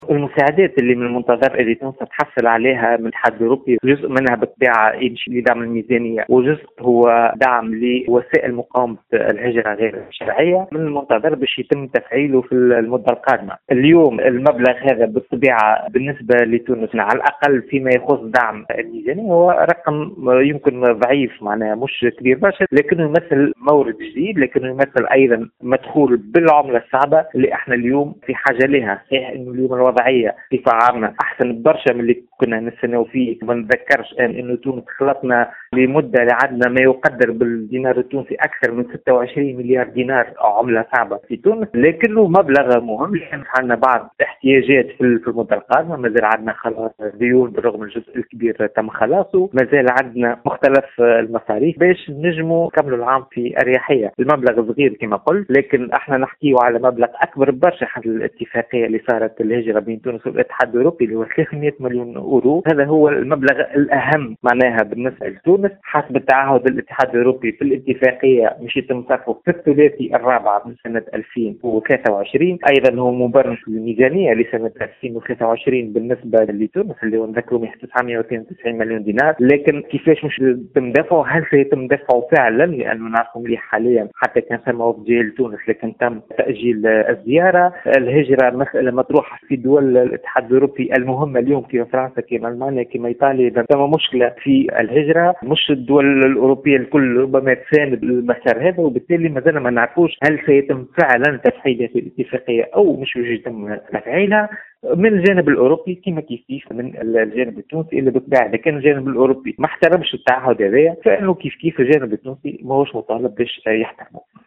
Dans une déclaration faite, ce jeudi 28 septembre 2023 à Tunisie Numérique, il a ajouté que ” Même si le montant alloué pour soutenir le budget de l’État tunisien est considéré “comme faible” car il représente une nouvelle ressource et un revenu important en devises, ce qui permettra de couvrir certains besoins et dépenses au cours de la période à venir pour mettre la fin de la crise actuelle (…).